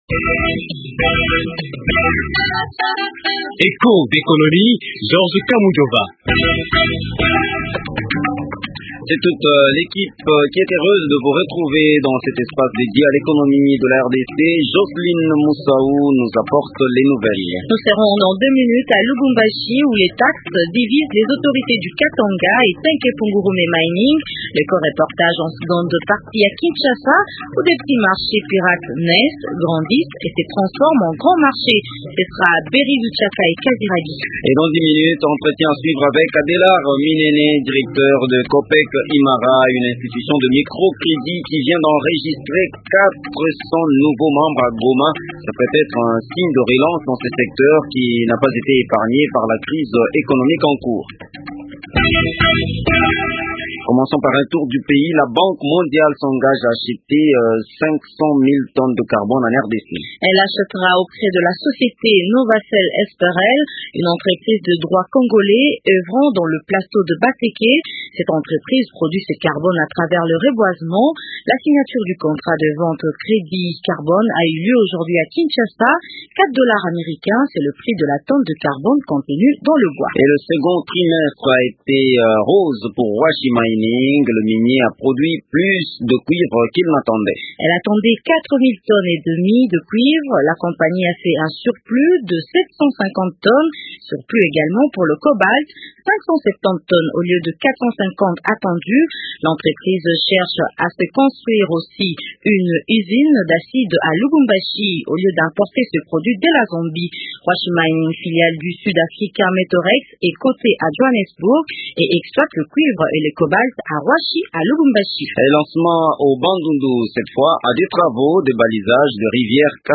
Un reportage que vous propose Echos d’Economie. L’émission évoque également cette coopérative des crédits du Nord Kivu qui enregistre 400 membres de plus en deux jours.